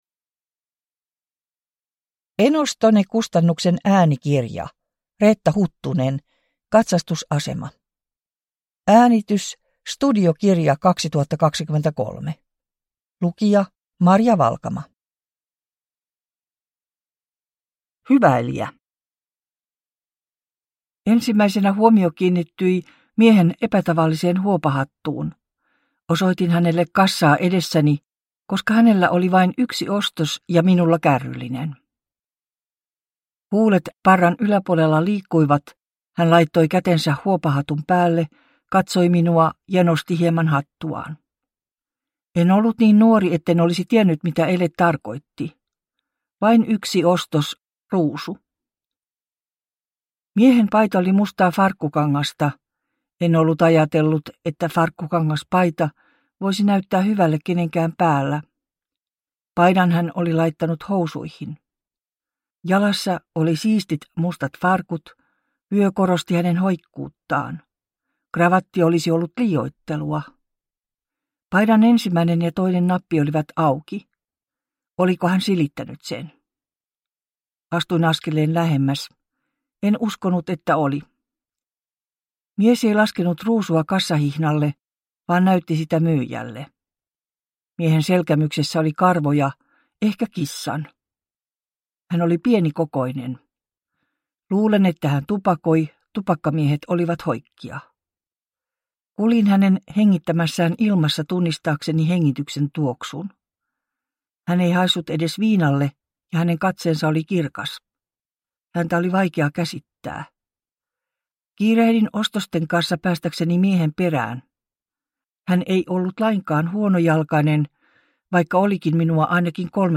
Katsastusasema – Ljudbok